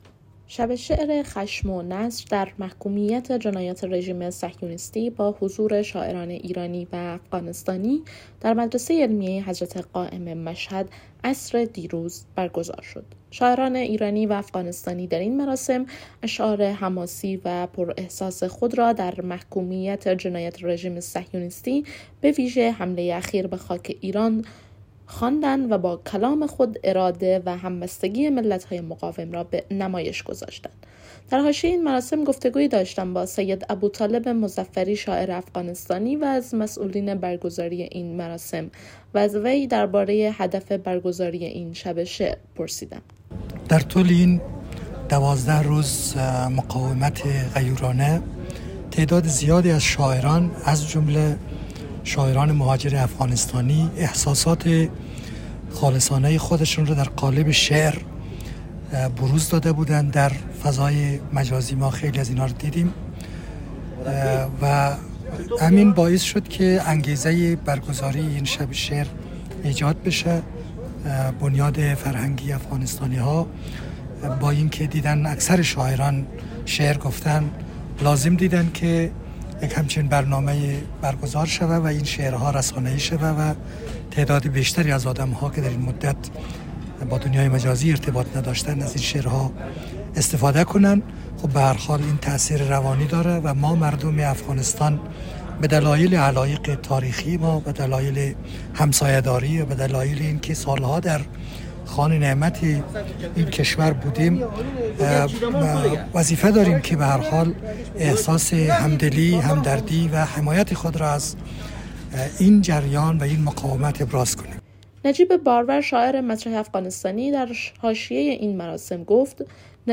شب شعر خشم و نصر در محکومیت جنایات رژیم صهیونیستی با حضور شاعران ایرانی و افغانستانی در مدرسه علمیه حضرت قائم (عج) مشهد عصر روز چهارشنبه برگزار شد.